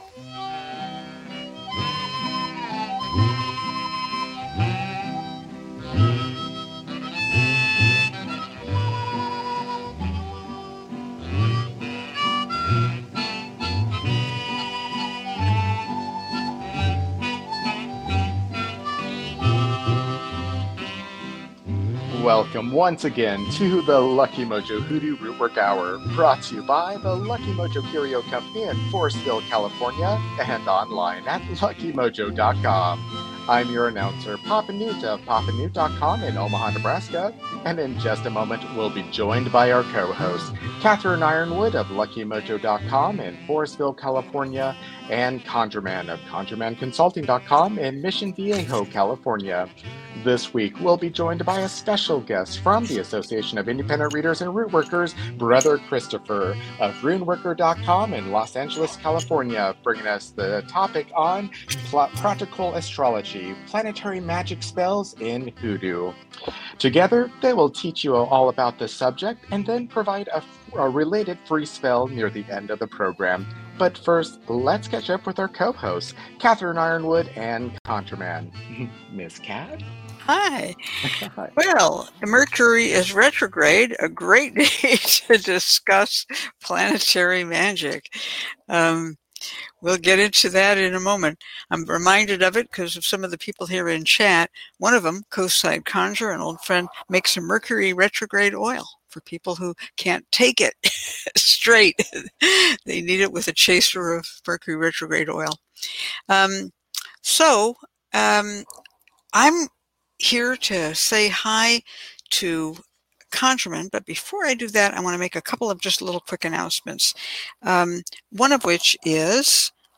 Planetary Magic Spells in Hoodoo followed by free psychic readings, hoodoo spells, and conjure consultations, giving listeners an education in African-American folk magic.